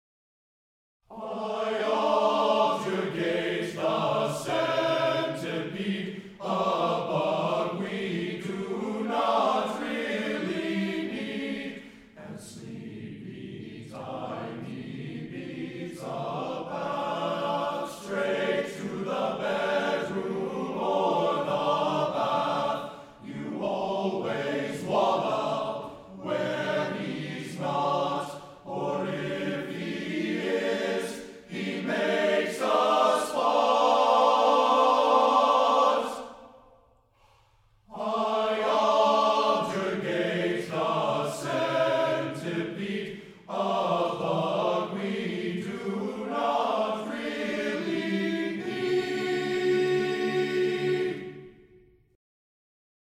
TTBB Chorus a cappella